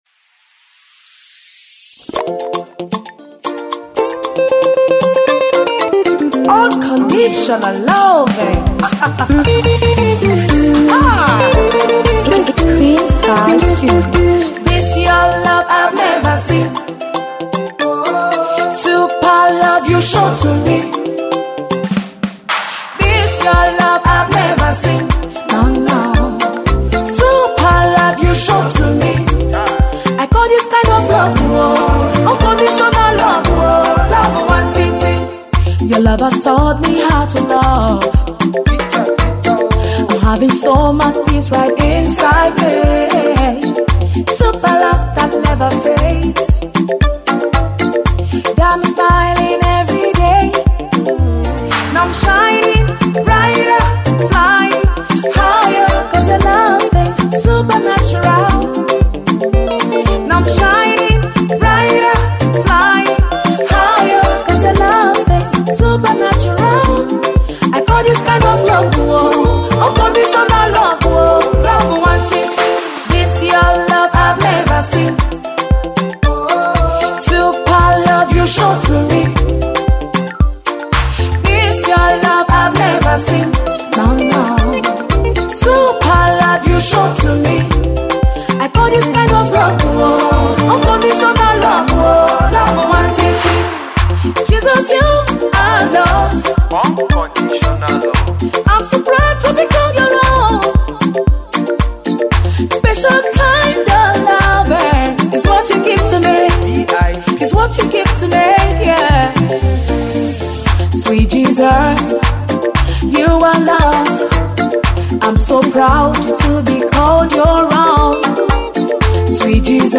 inspirational song